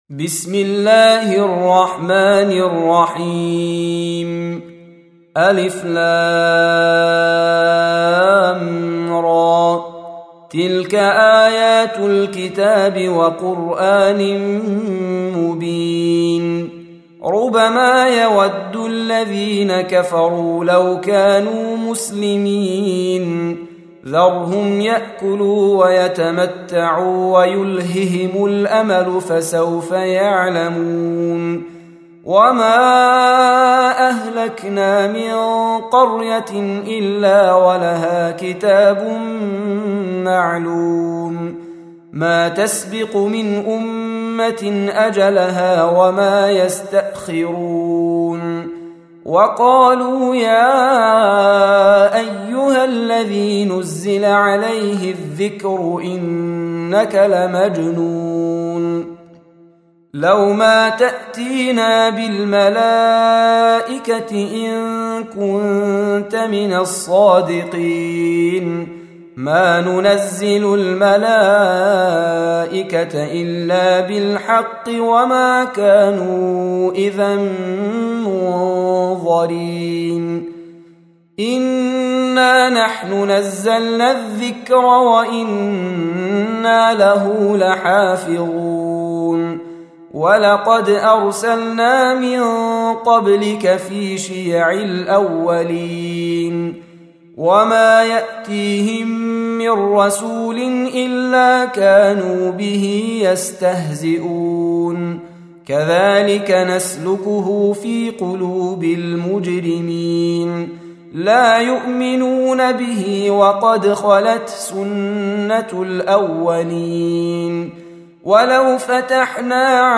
Surah Repeating تكرار السورة Download Surah حمّل السورة Reciting Murattalah Audio for 15.